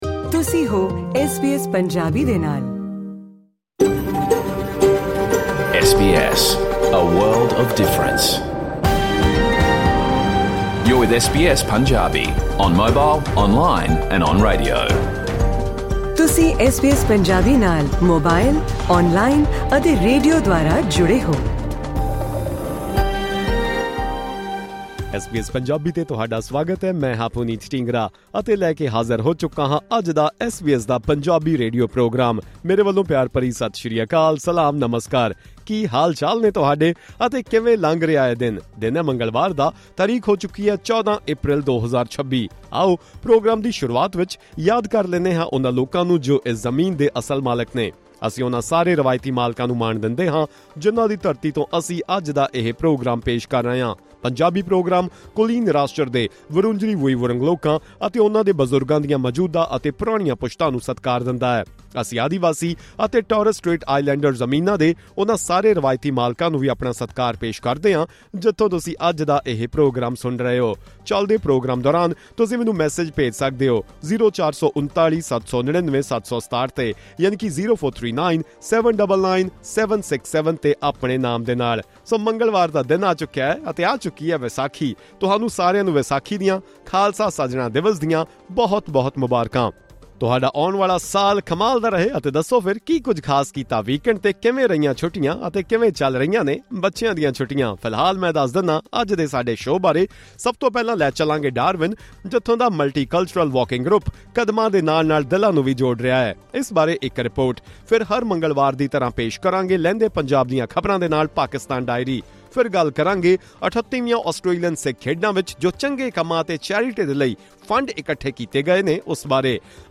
Listen to the SBS Punjabi full radio program: Vaisakhi special